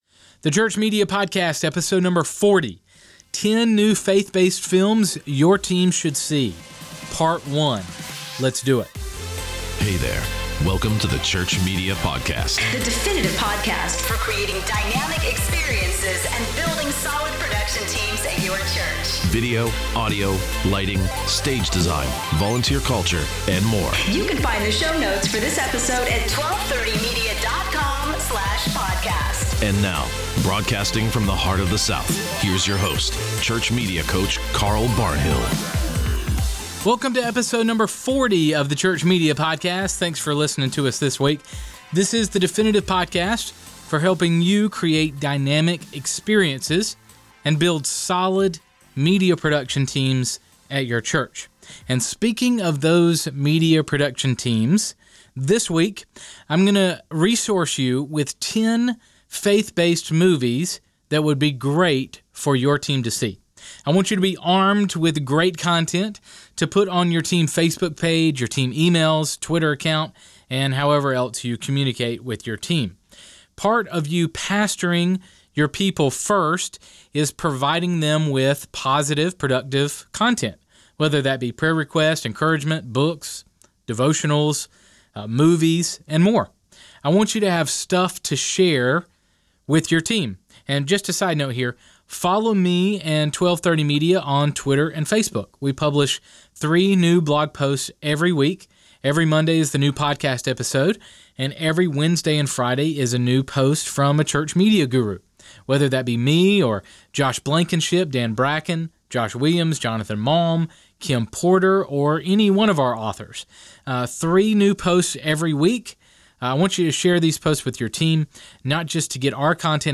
We’ve packed some interview clips that we’ve done with some of the actors, directors, producers and other crew involved in the 10 films I’m going to mention.